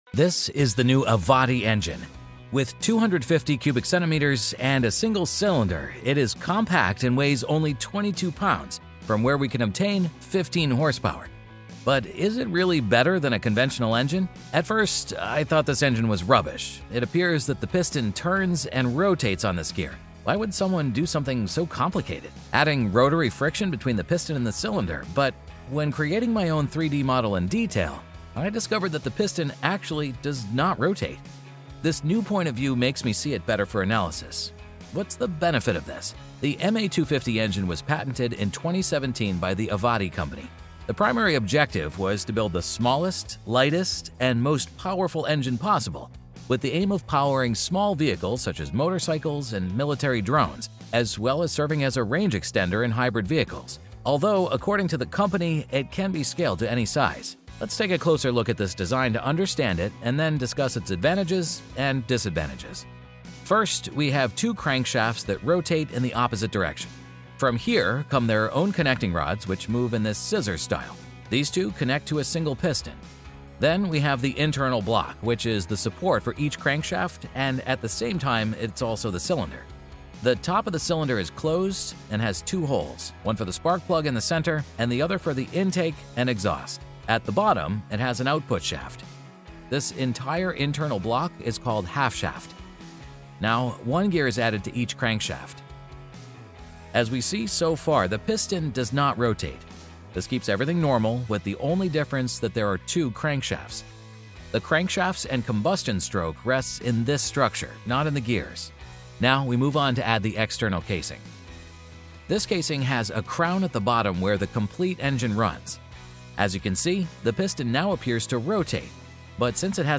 I like the sound as well sounds like a Honda.